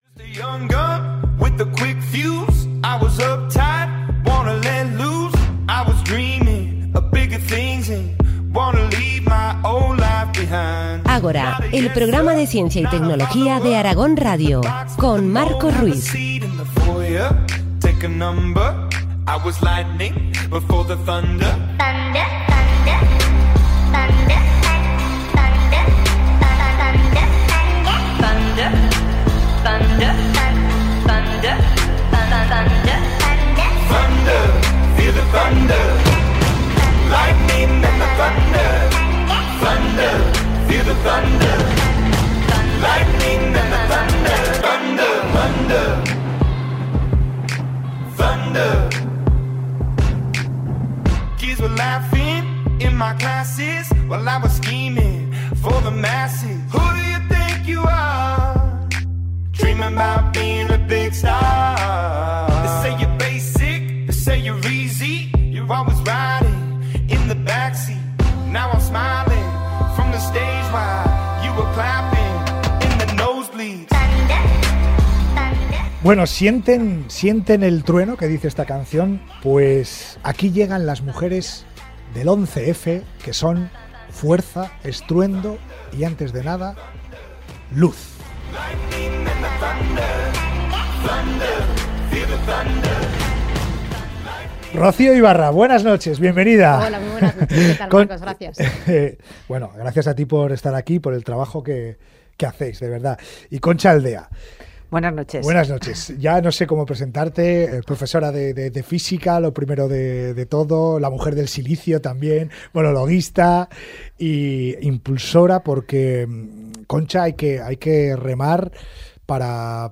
También puedes escuchar aquí el programa completo (encontrarás la entrevista en el fragmento 00:40:40 – 01:01:50)